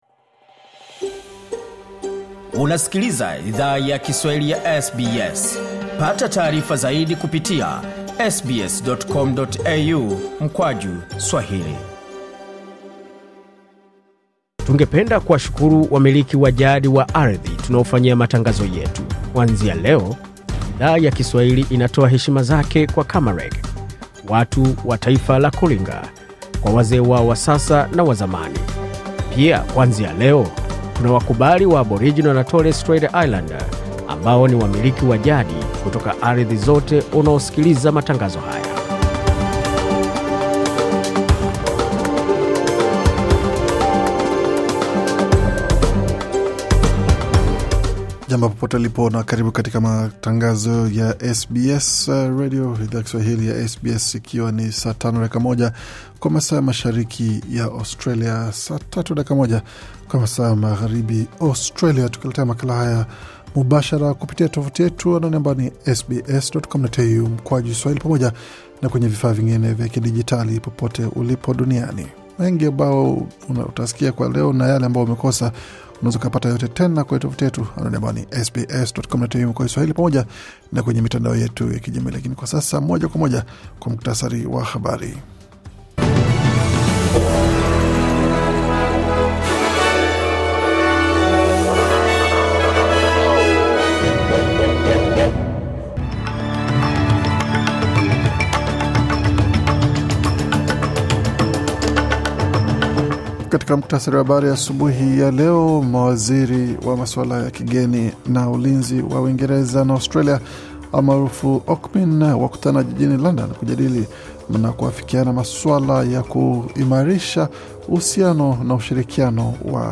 Taarifa ya Habari 17 Disemba 2024